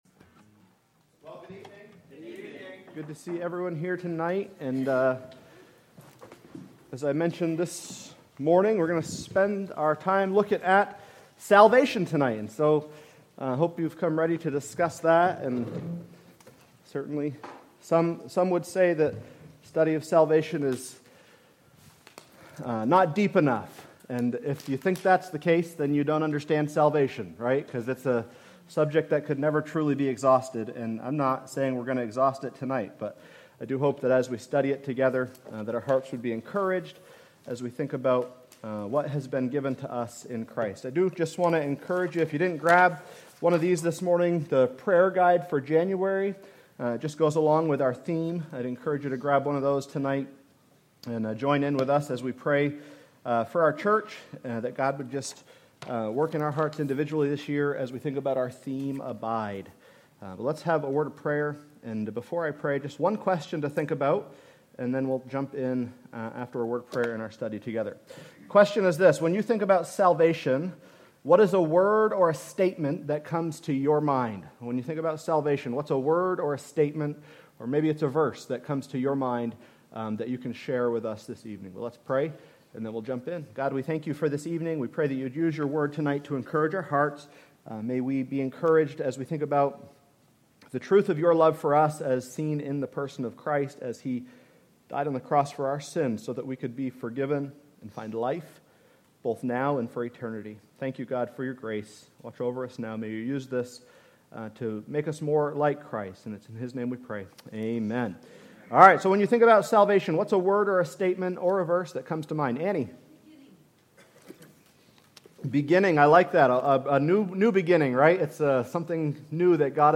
Sermons by Northside Baptist Church